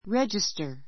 register rédʒistə r レ ヂ ス タ 名詞 ❶ 記録（簿 ぼ ）, 登録（簿）, ～（名）簿 ❷ レジスター ⦣ cash register ともいう.